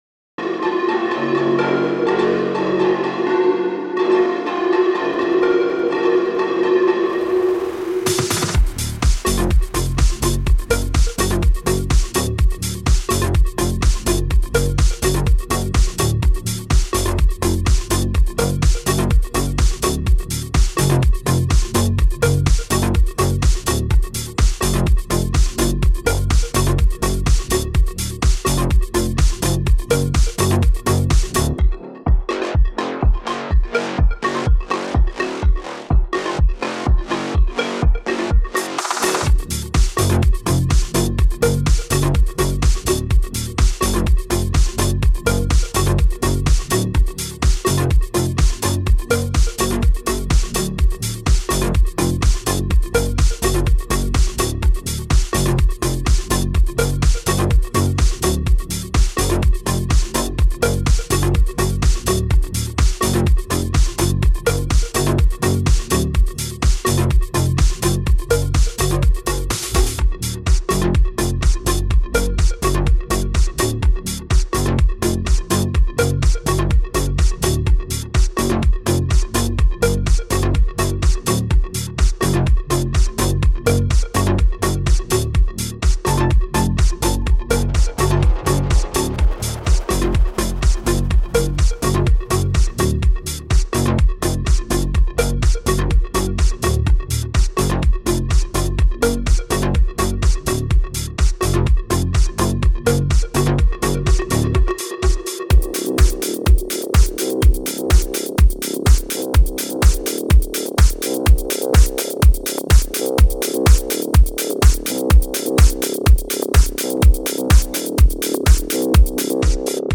Tech House